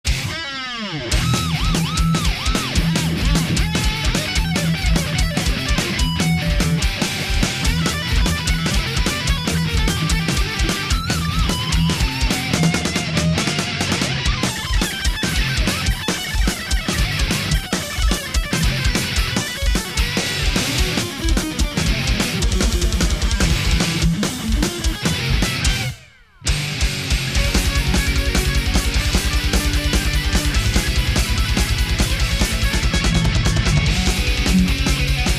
guitar solos